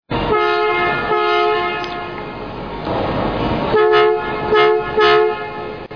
Aufgenommen in einen Werkhalle ..etwas laut im Hintergrund .. zuerst neben dem Fahrzeug dann davor